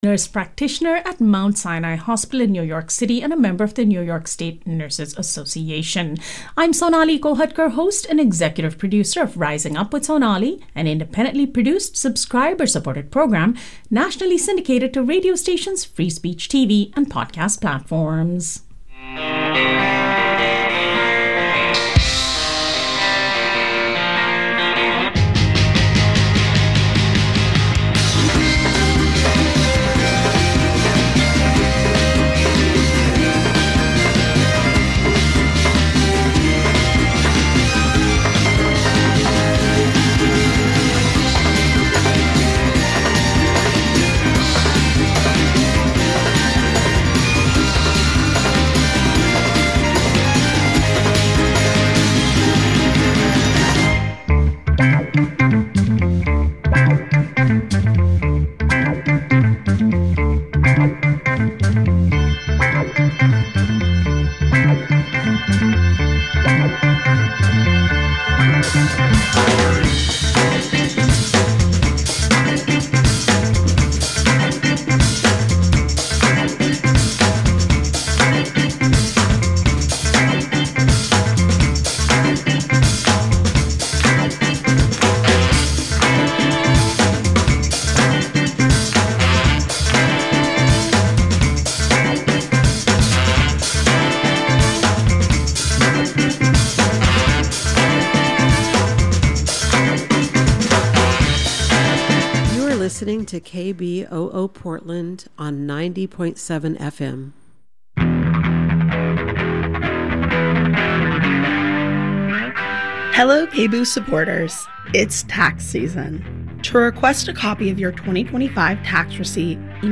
Topic tags: African American , Community Building , Women , Music